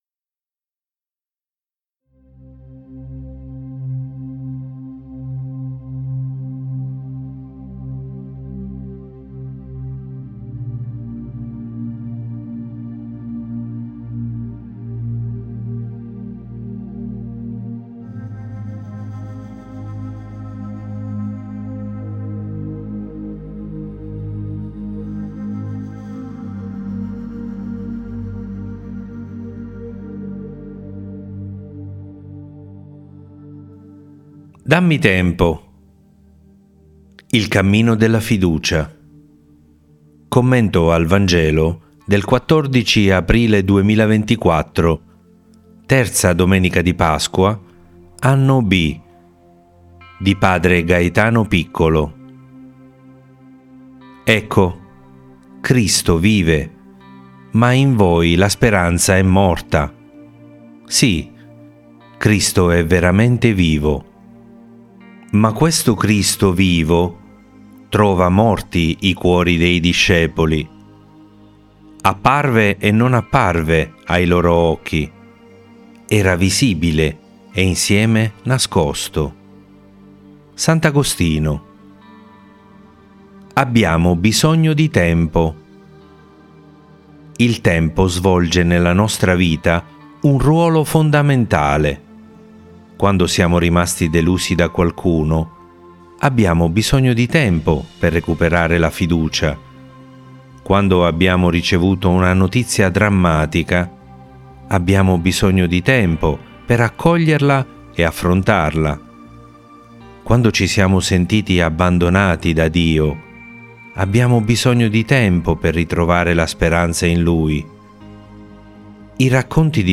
Dammi tempo! Il cammino della fiducia. Commento al Vangelo del 14 aprile 2024